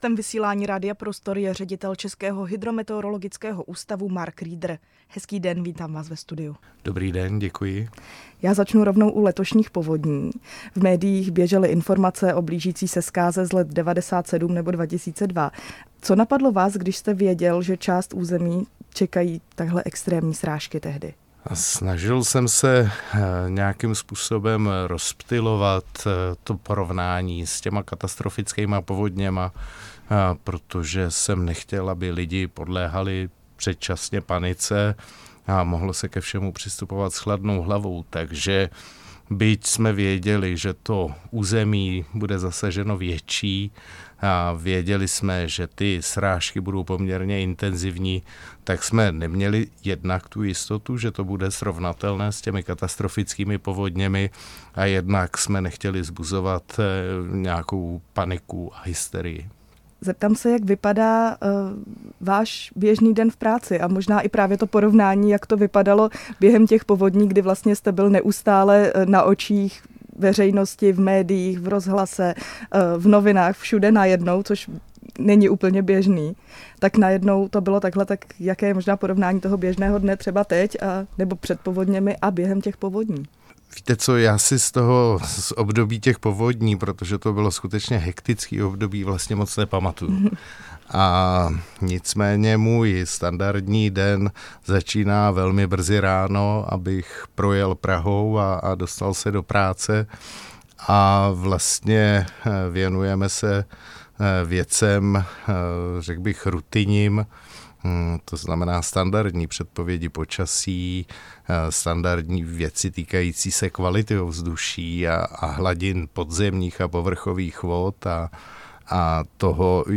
Ředitel Českého hydrometeorologického ústavu Mark Rieder se v rozhovoru pro Radio Prostor ohlíží za hektickým obdobím letošních povodní, které ukázaly, jak klíčová je včasná předpověď a spolupráce s dalšími složkami. Vysvětluje, jak se meteorologie vyrovnává s rostoucími nároky, využívá umělou inteligenci a čelí důsledkům klimatické změny. Přiznává, že jeho práce je pod neustálým dohledem veřejnosti, a zdůrazňuje, že v předpovědích nejde o jistotu, ale o pravděpodobnost.